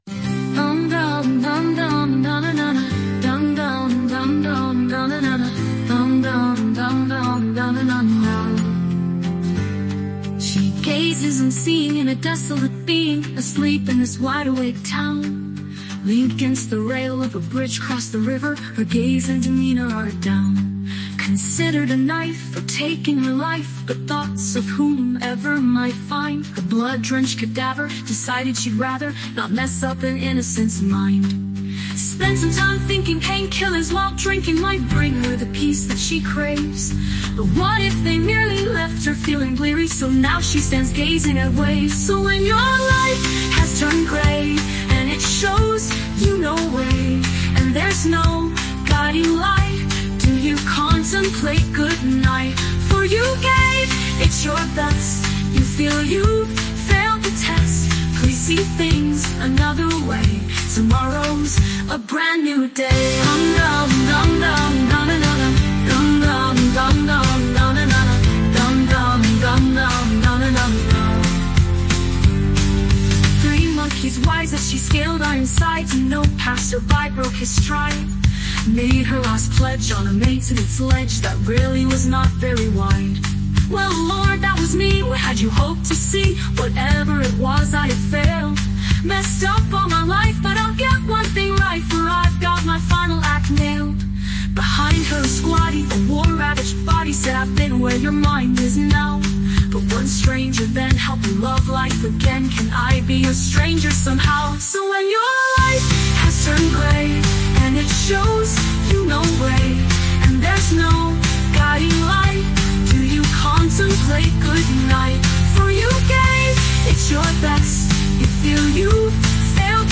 I have to say, getting AI to produce a decent musical version of this song was nightmarish.
Even this version is a little upbeat for my liking, but I had to call it a day sooner or later, so I settled on this tune… which actually did grow on me.